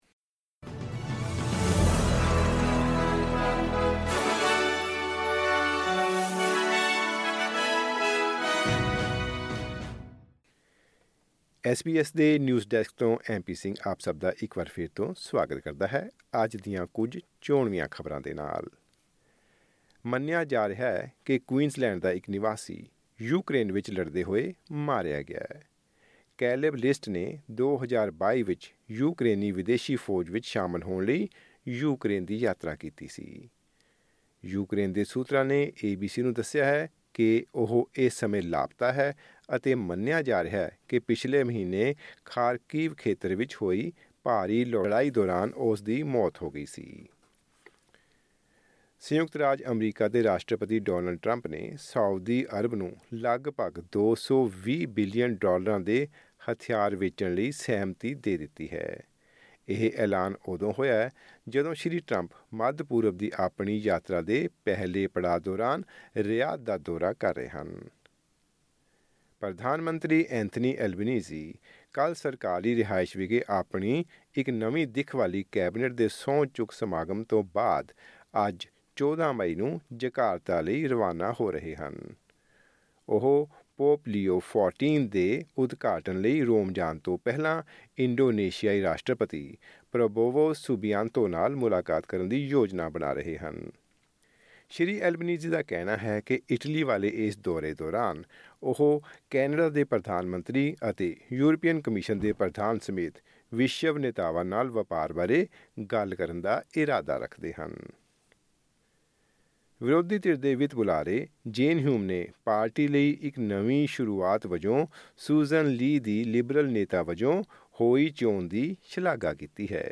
ਖਬਰਨਾਮਾ: ਭਾਰਤ ਨੇ ਪਾਕਿਸਤਾਨੀ ਹਾਈ ਕਮਿਸ਼ਨ ਅਧਿਕਾਰੀ ਨੂੰ 24 ਘੰਟਿਆਂ ਅੰਦਰ ਦੇਸ਼ ਛੱਡਣ ਦਾ ਦਿੱਤਾ ਹੁਕਮ